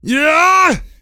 XS长声01.wav
XS长声01.wav 0:00.00 0:01.02 XS长声01.wav WAV · 88 KB · 單聲道 (1ch) 下载文件 本站所有音效均采用 CC0 授权 ，可免费用于商业与个人项目，无需署名。
人声采集素材